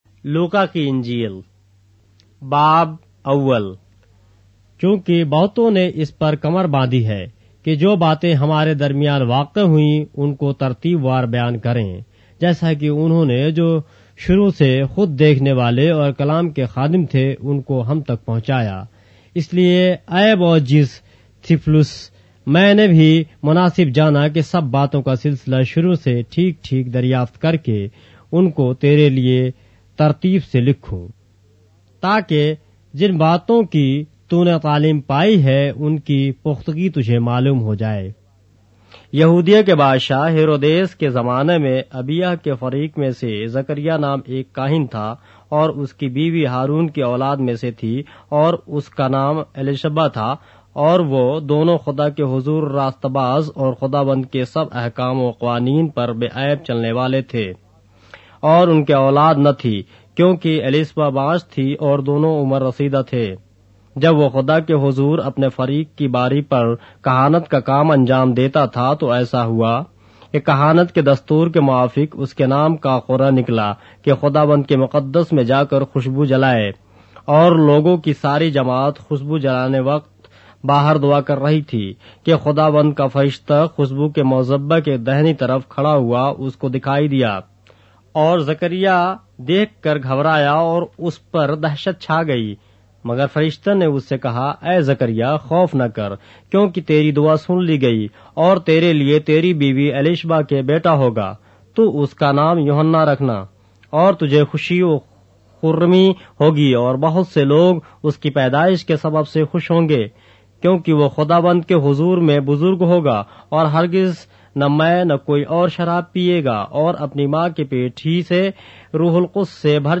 اردو بائبل کے باب - آڈیو روایت کے ساتھ - Luke, chapter 1 of the Holy Bible in Urdu